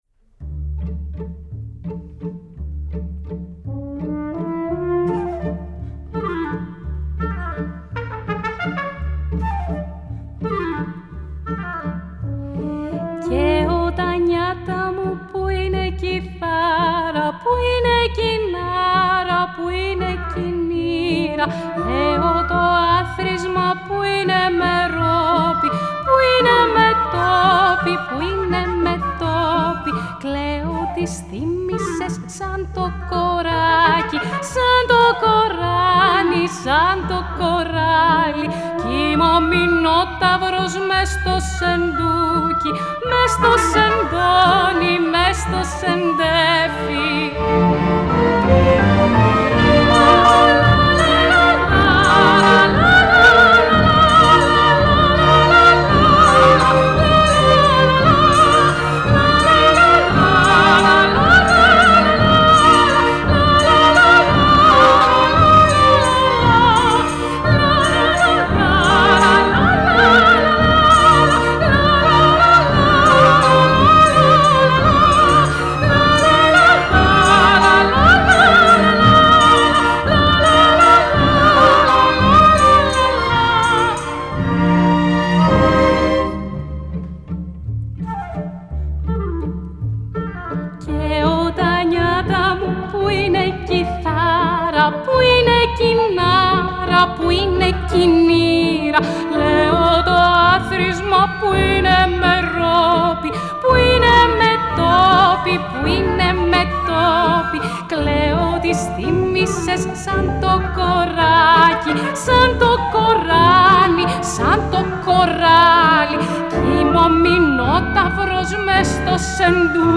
Ηχογράφηση στο STUDIO B, Παρασκευή 16 Οκτωβρίου 2020
Στο πιάνο ο συνθέτης